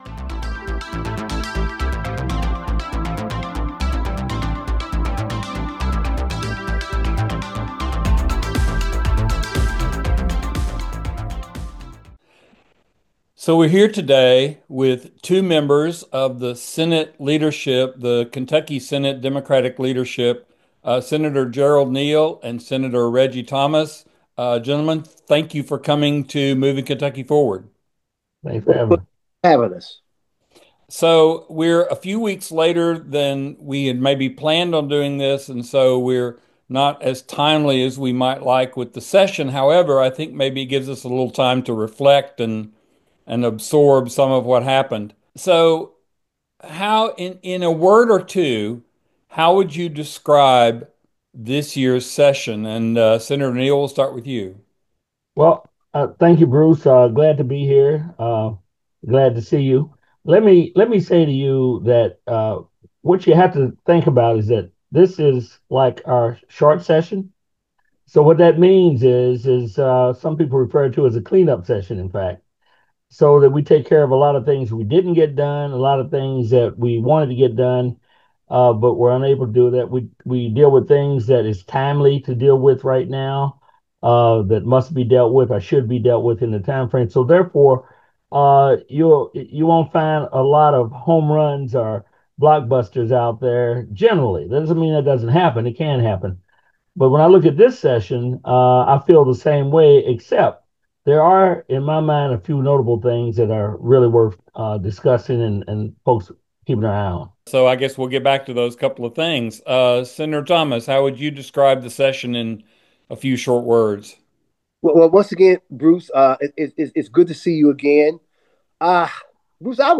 Moving Kentucky Forward An Interview with Senate Dem Leadership